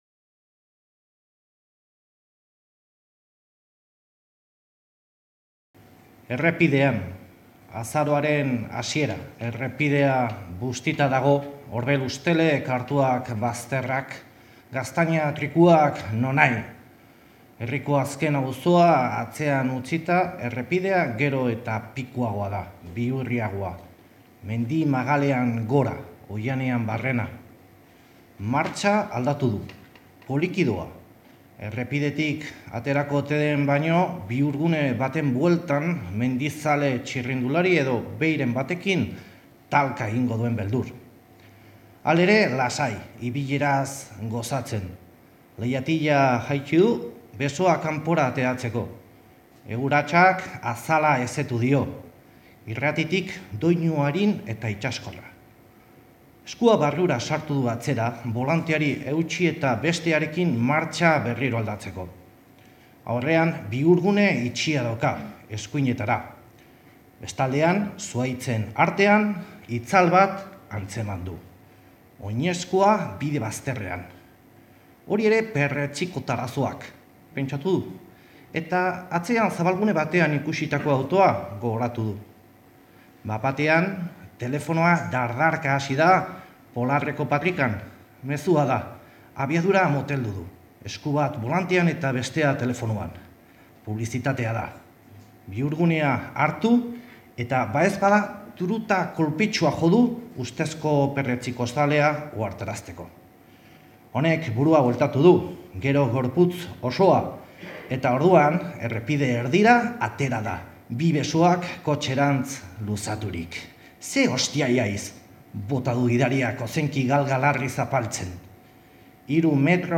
bere ipuina irakurri digu.